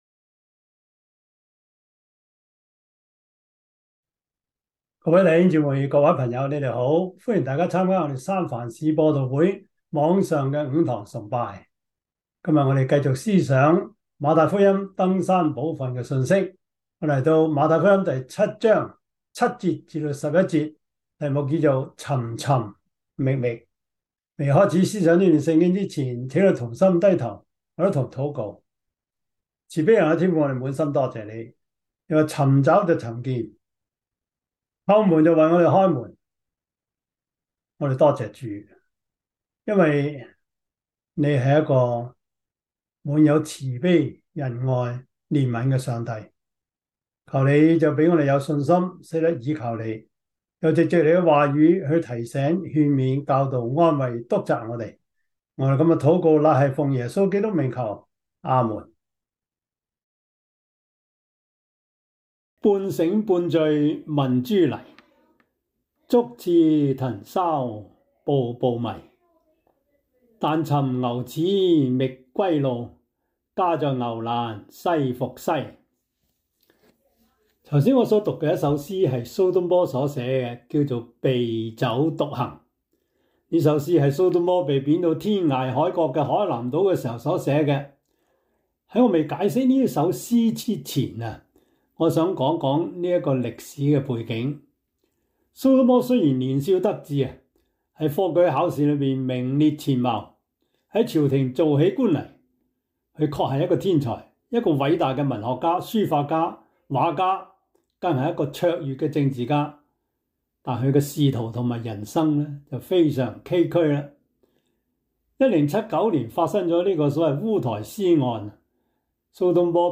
馬太福音 7:7-11 Service Type: 主日崇拜 馬太福音 7:7-11 Chinese Union Version